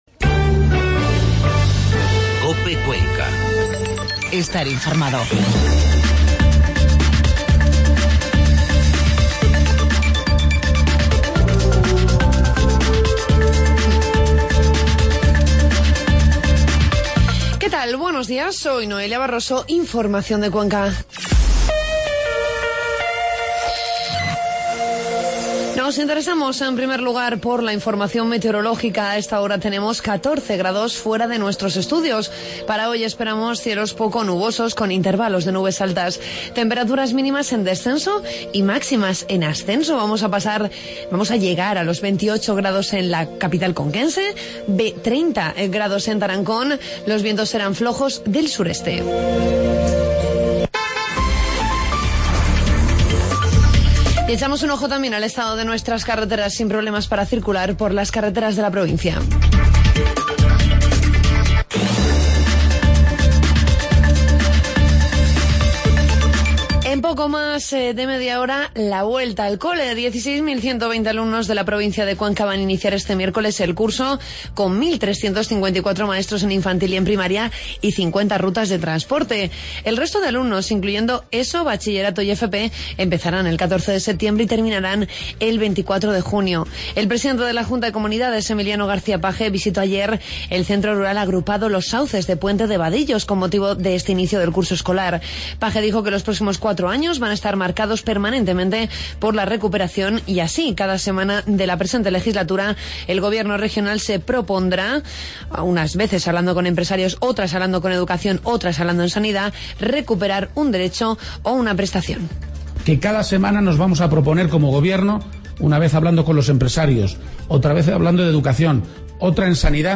Informativo matinal miércoles 9 de septiembre